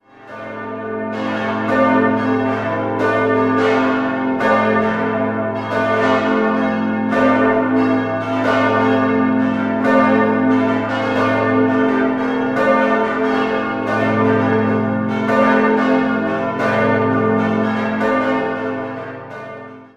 5-stimmiges TeDeum-Gloria-Geläute: a°-c'-d'-e'-g' Die Glocken 1, 2, und 5 wurden 1952 von der Gießerei Rincker gegossen.